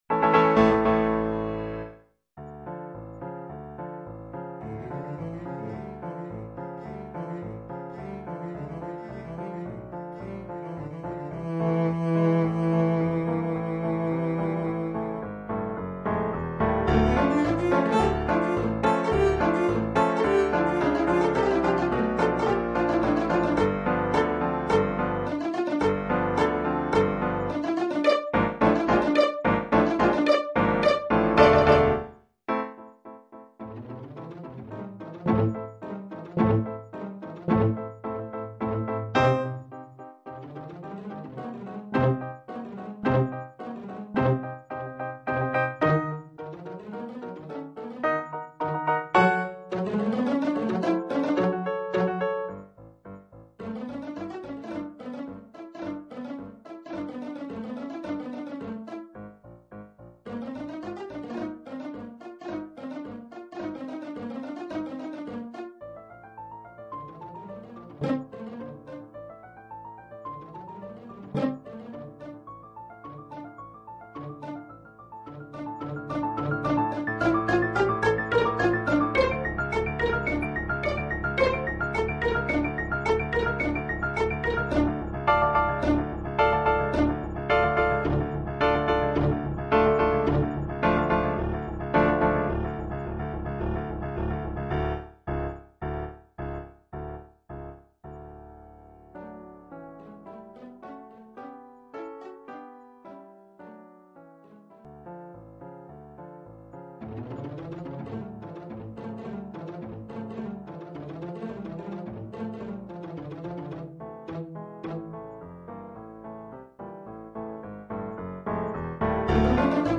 transcrite pour violoncelle et piano
Niveau : moyen.